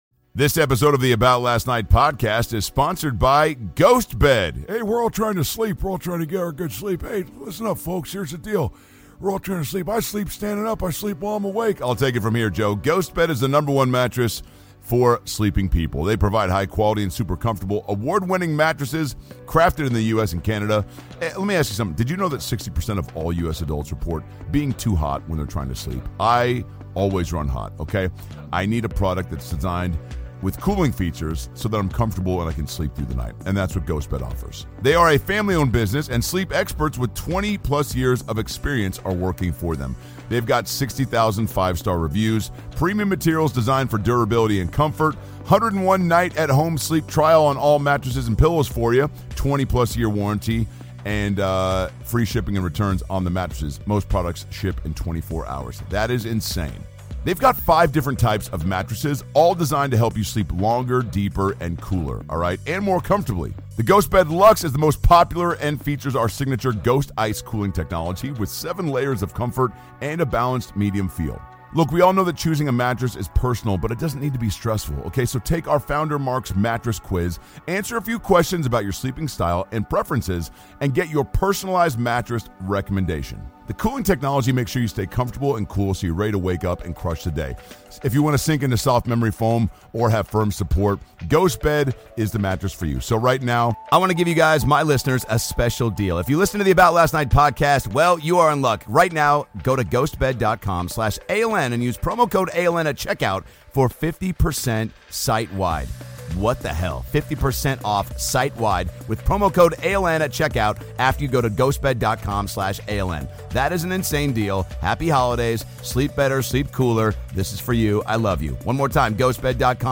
Dr Phil LIVE! is coming out of Beacon Theater in NYC with interviews from Jason Biggs, Jessica Kirson, Godfrey, and, of course, The Pie! Also, a very special performance by Johnny Rzeznik of The Goo Goo Dolls.